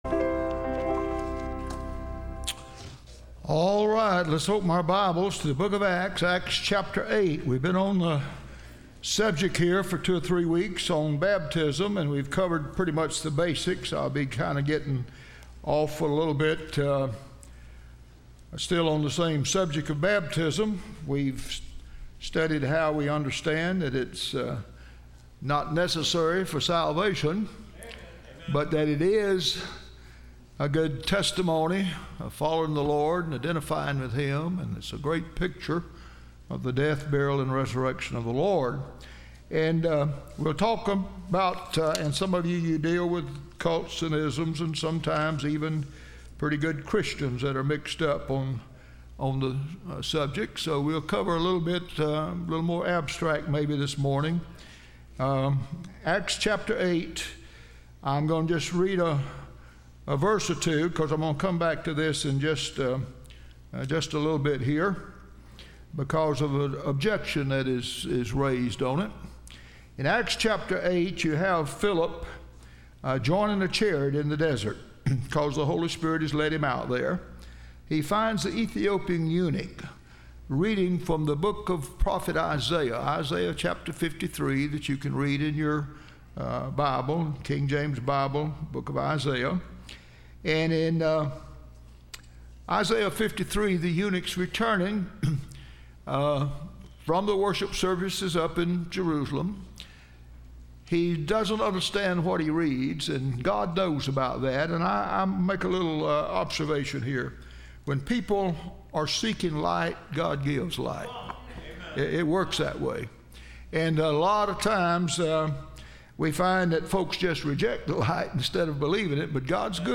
Series: Study of Baptism Service Type: Sunday School